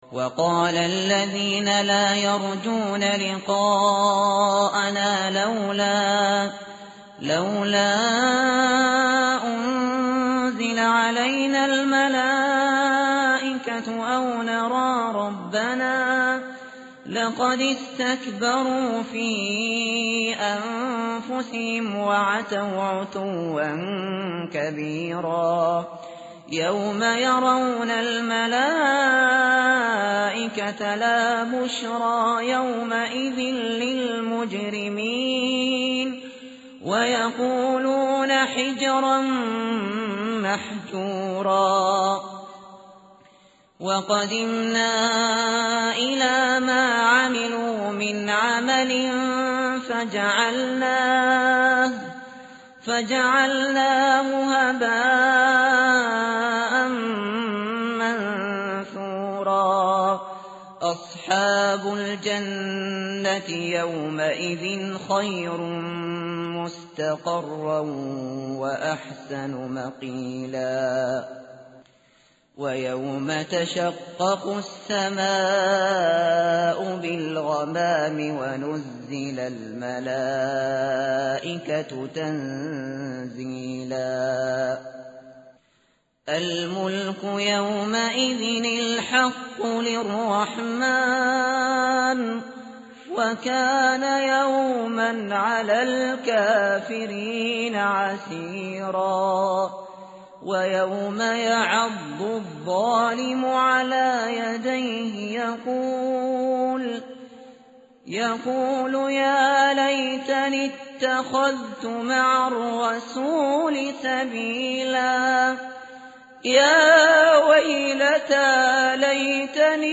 tartil_shateri_page_362.mp3